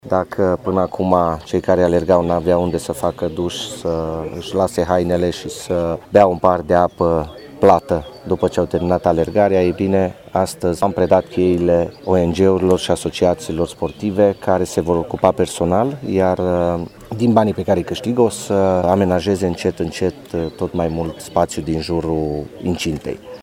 Viceprimarul municipiului Tîrgu-Mureş, Claudiu Maior.